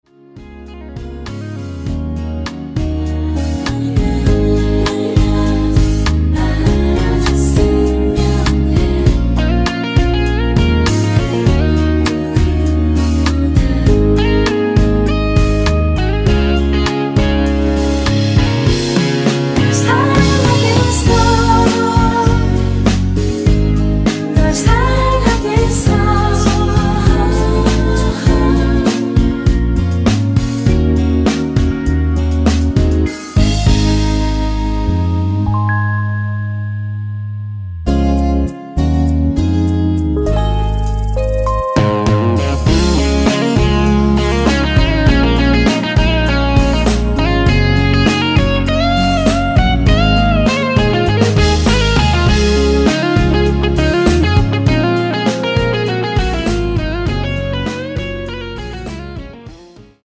코러스 포함된 MR 입니다.(미리듣기 참조)
Db
앞부분30초, 뒷부분30초씩 편집해서 올려 드리고 있습니다.
중간에 음이 끈어지고 다시 나오는 이유는